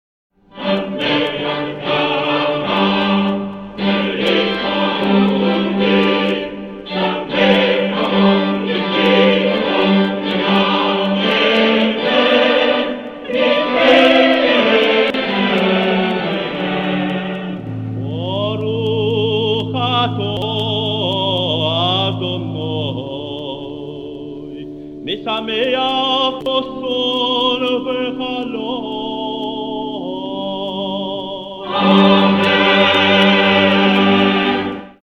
6e bénédiction (rite ashkenaze
accompagné à l’orgue